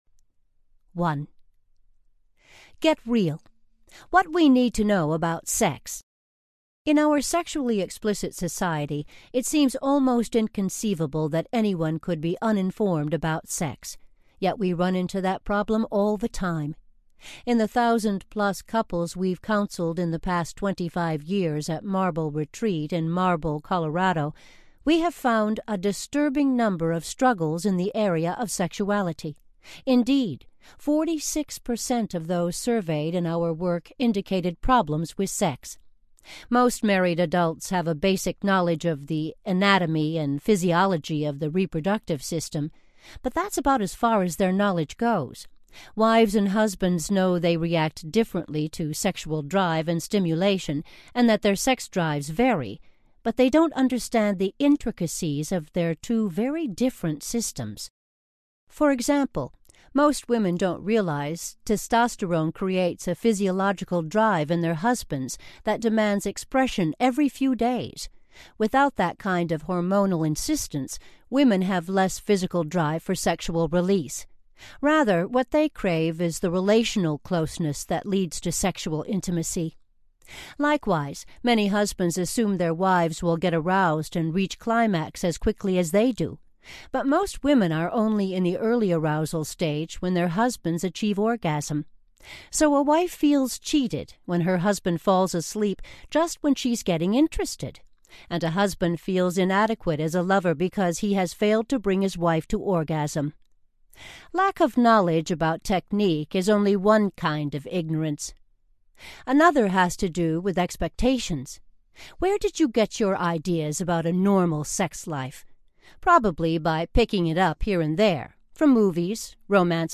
Real Questions, Real Answers about Sex Audiobook
Narrator
9.5 Hrs. – Unabridged